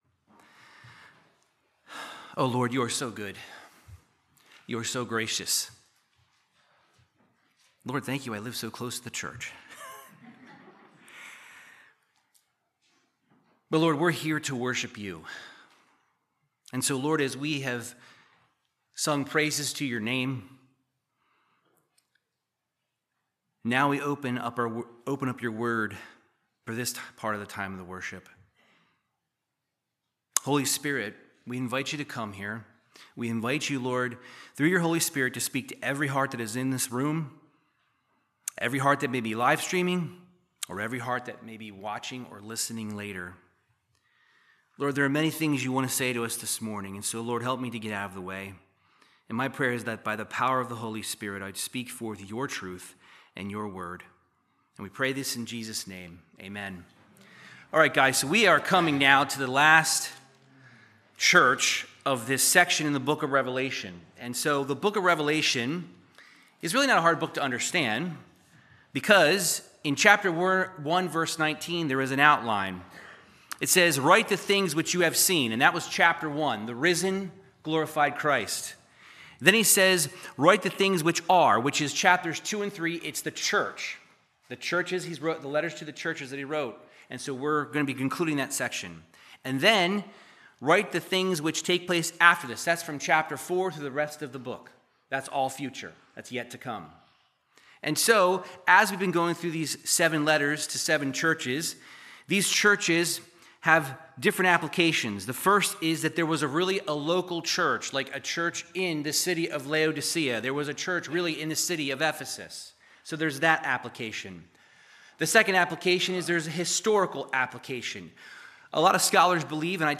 Verse by verse Bible teaching through the book of Revelation 3:14-22